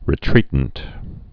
(rĭ-trētnt)